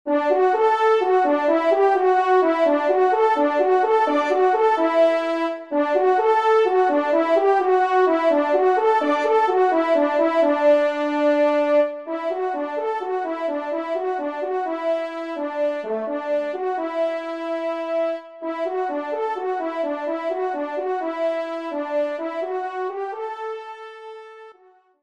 Arrangement Trompe et Piano
1e Trompe